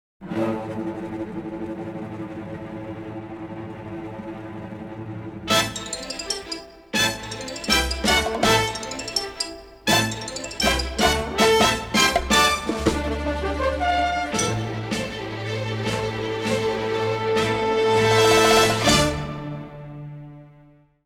memorable, energetic and varied score